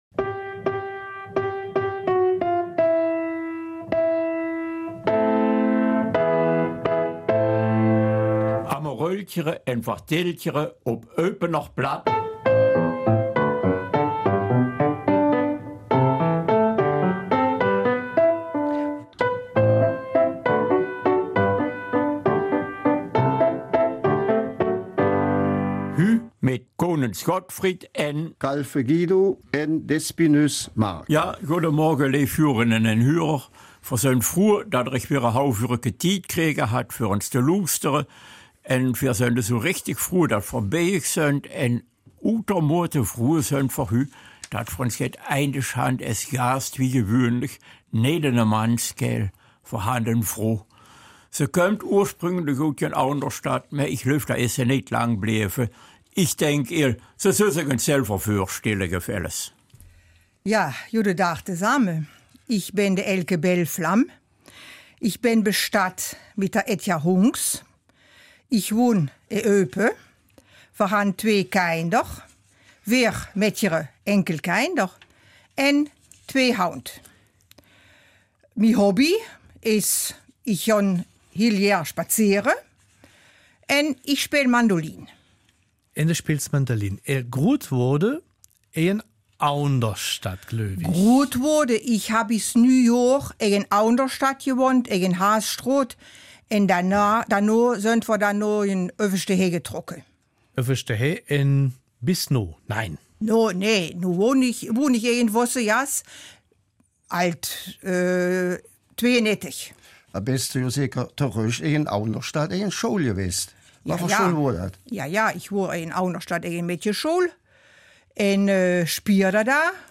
Eupener Mundart: Mandolinen im Sonnenschein
Jetzt schon dürfen wir euch verraten, dass das ausgesuchte Musikstück unseres Gastes ein fantastisches Musikwerk, gespielt vom Eupener Mandolinenorchester, sein wird.